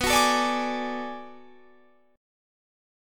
Listen to Bm13 strummed